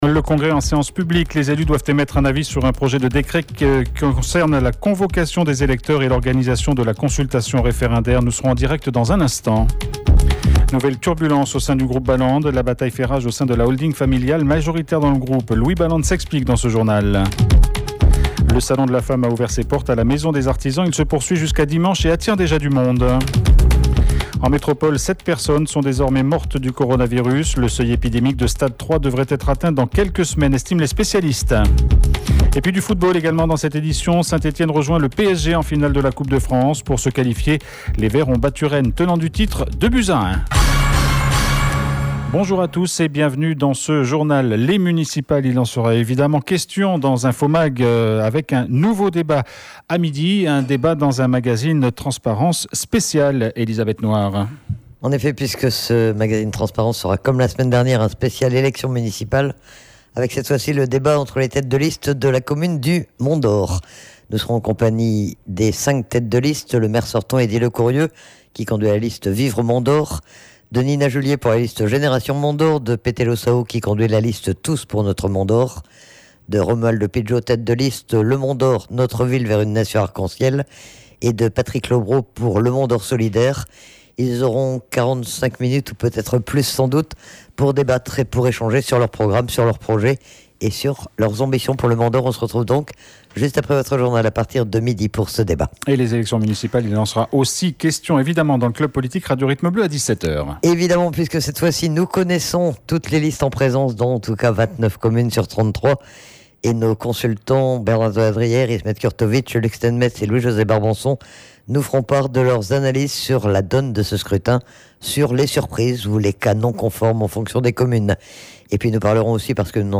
JOURNAL : 06/03/20 MIDI